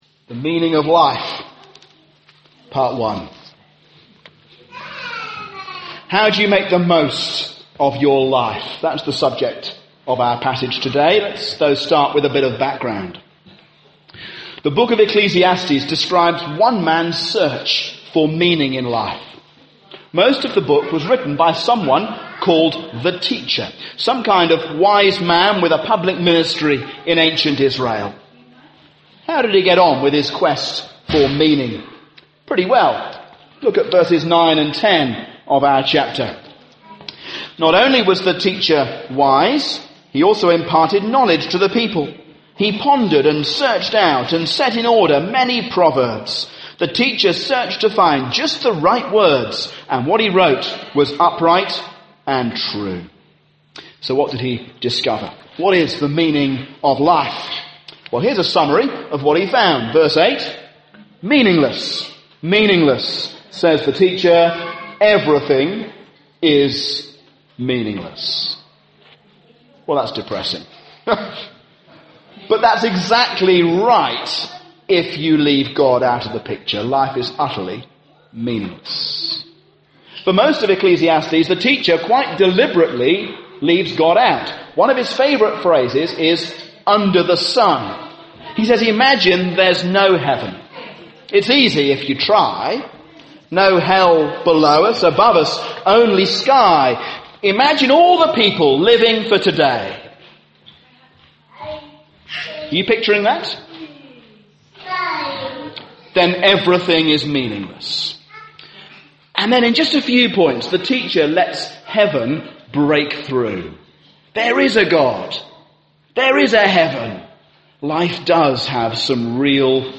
A sermon on Ecclesiastes 12